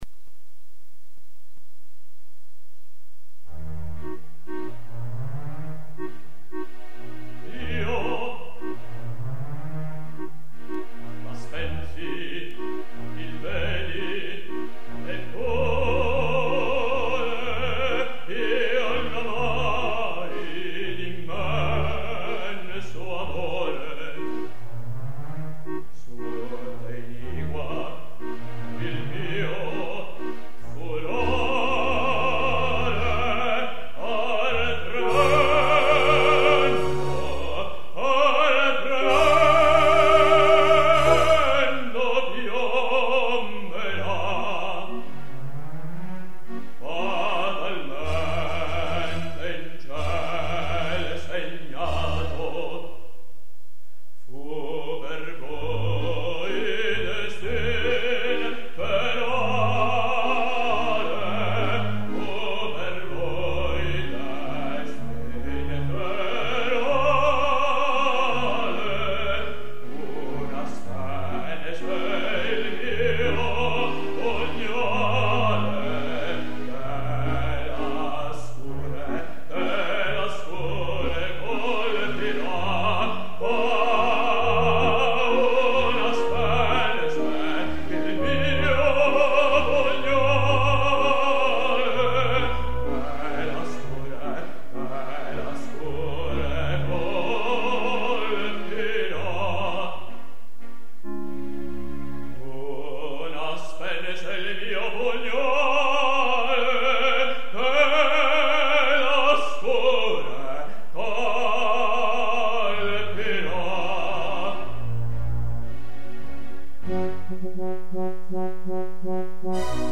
baritono 05:05